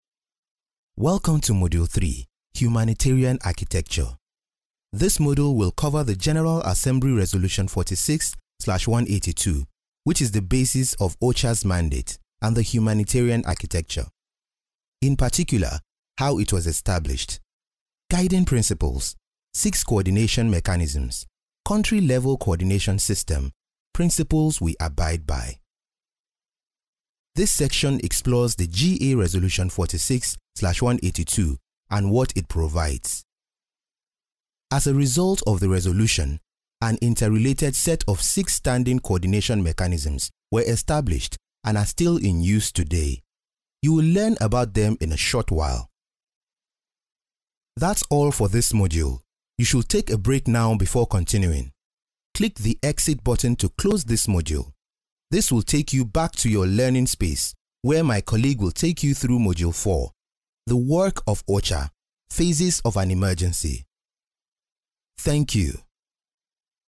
Sprechprobe: eLearning (Muttersprache):
Clients from around the world choose me for their voice over projects because of the warmth, realness and authenticity i bring to each project. I work from my professional grade studio doing commercials, internet audio, narrations, documentaries, E-learning modules and so many other forms of voice over work.
I speak an unaccented English and can do an African accent perfectly; i also speak Hausa and Yoruba languages fluently.